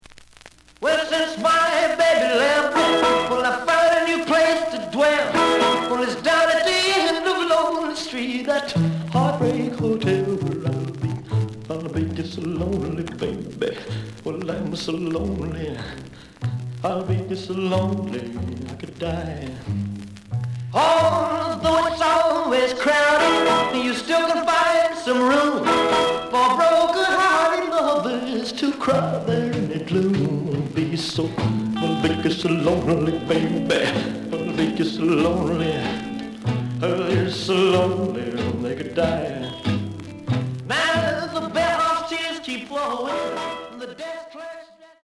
The listen sample is recorded from the actual item.
●Genre: Rhythm And Blues / Rock 'n' Roll
Noticeable noise on both sides.)